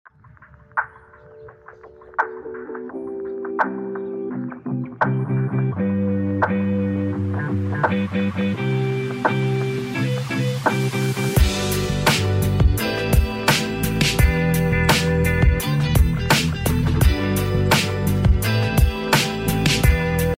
Watching a turtle walking on sound effects free download
Watching a turtle walking on a sidewalk enjoying the nice summer weather